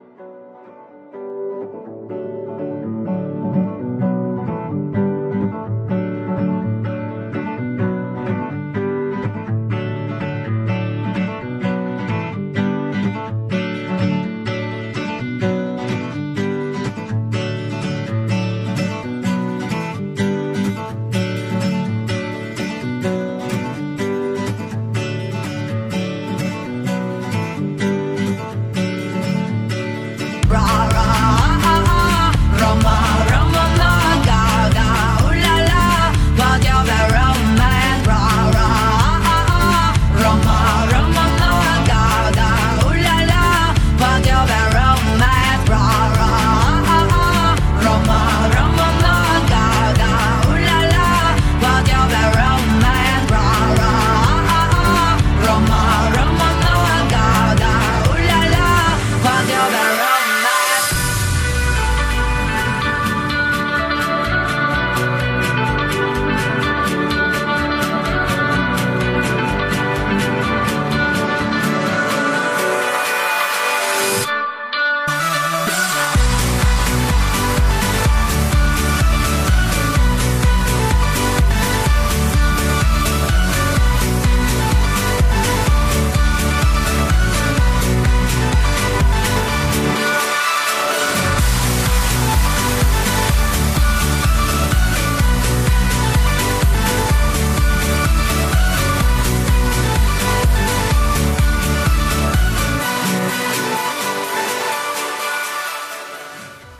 These are unauthorized bootlegs.
Genres: Hip Hop, Rock, Top 40